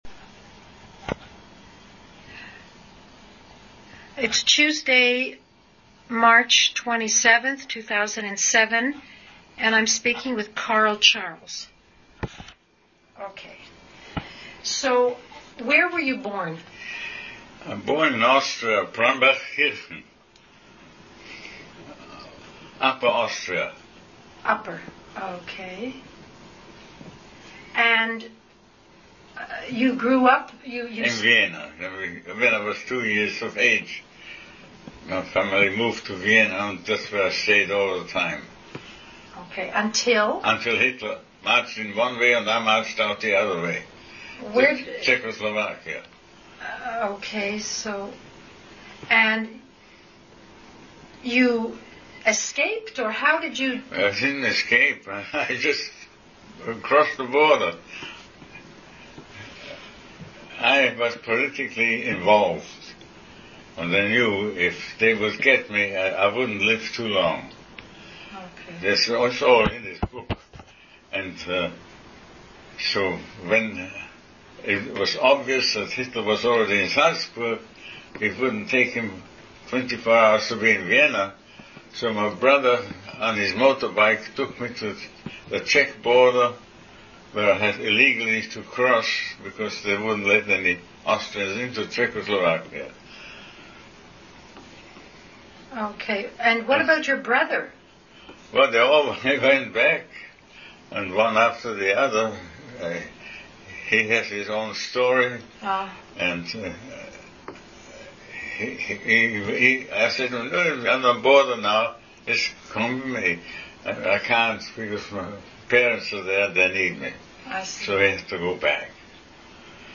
• Canadian Military Oral History Collection
• Digital sound recording in .wav format at 16 bits and 44 kHz.